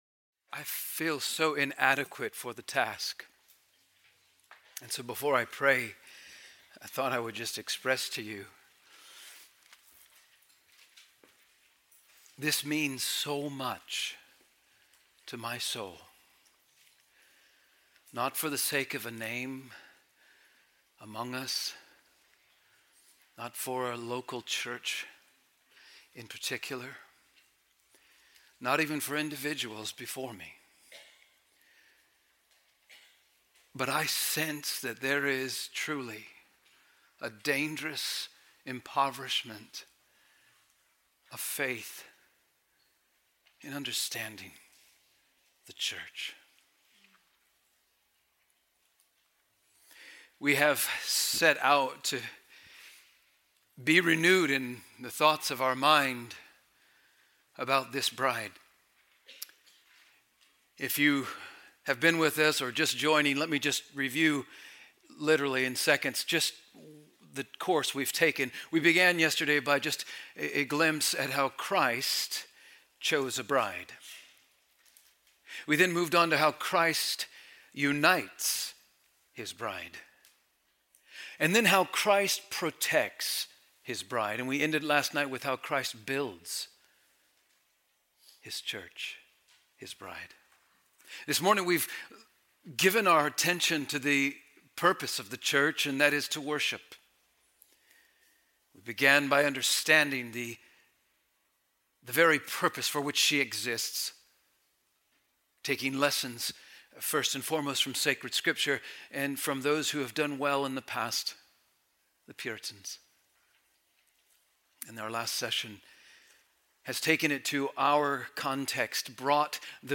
Coram Deo Conference 2025